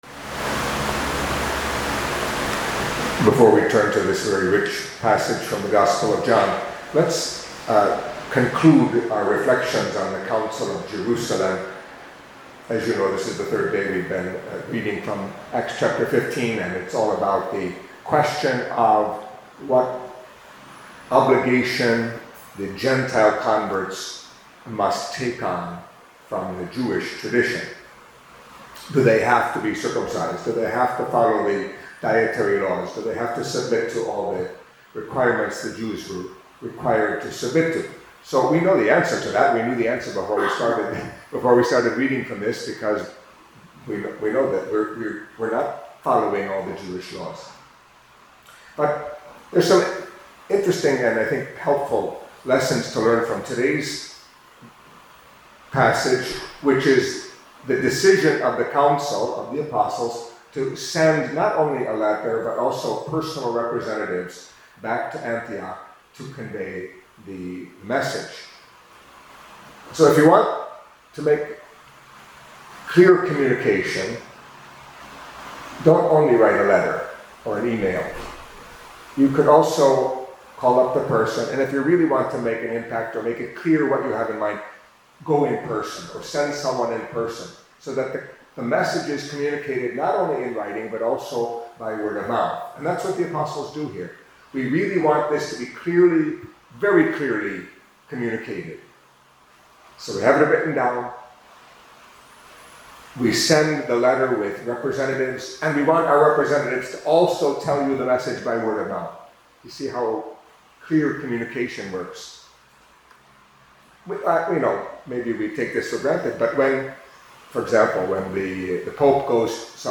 Catholic Mass homily for Friday of the Fifth Week of Easter